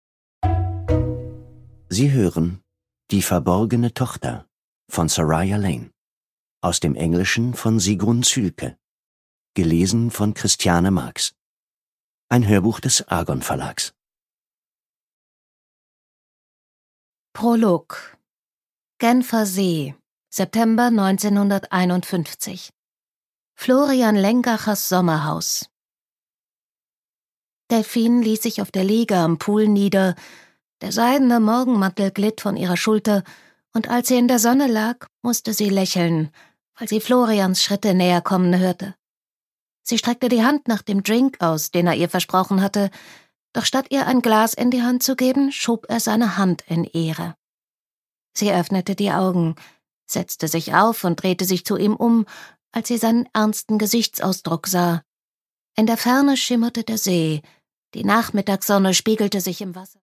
MP3 Hörbuch-Download